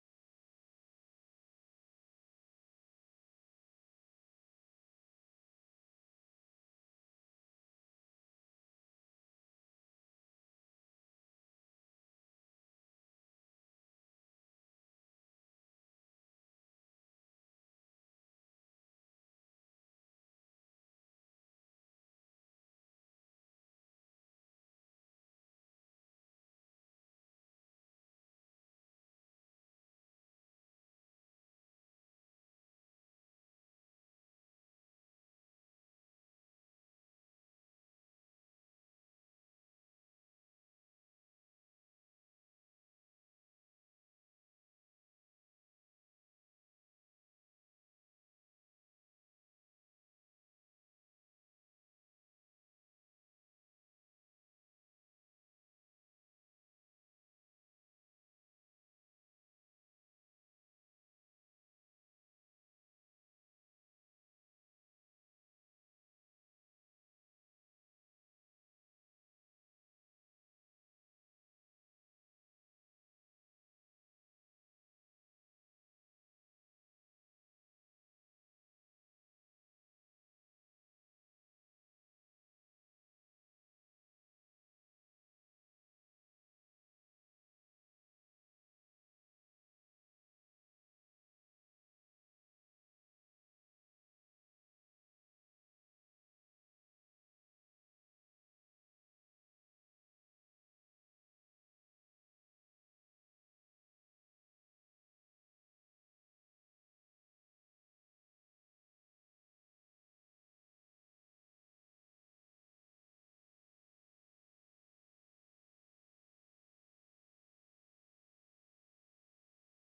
Responsibility-Building-Part-1-Sermon-Audio-CD.mp3